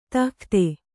♪ tahkhte